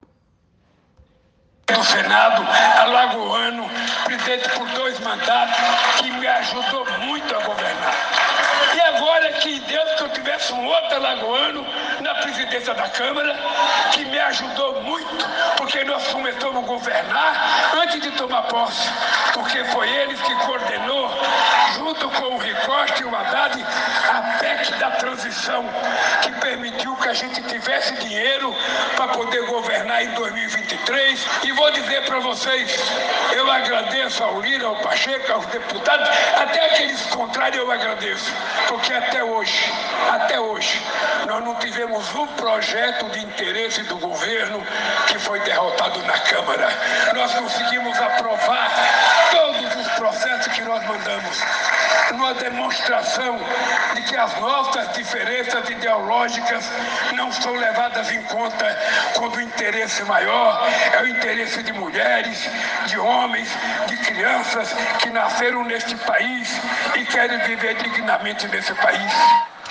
Lula agradece apoio de Arthur Lira em evento em Alagoas
O presidente Luiz Inácio Lula da Silva expressou sua gratidão ao presidente da Câmara Federal, Arthur Lira, durante um evento em São José da Tapera (AL), onde foi assinada a ordem de serviço para uma obra que visa levar água ao sertão alagoano.